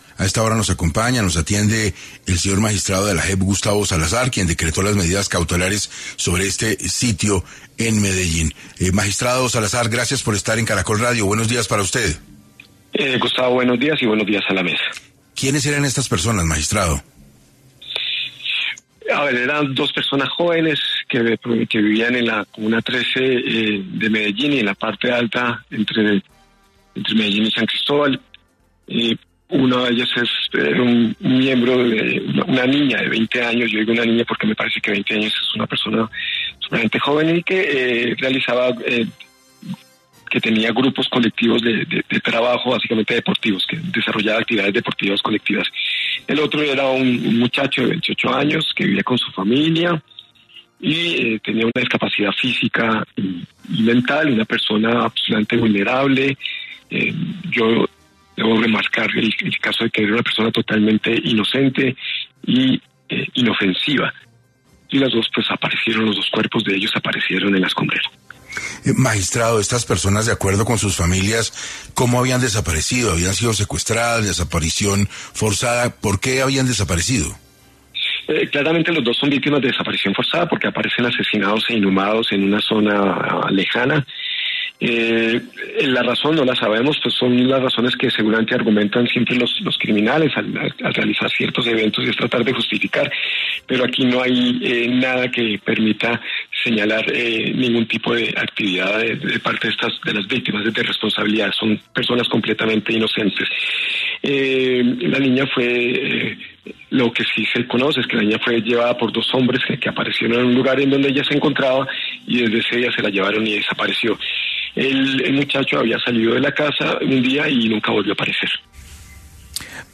Gustavo Salazar, magistrado de la JEP habló hoy para 6AM sobre los nuevos hallazgos que se han realizado en la fosa común la Escombrera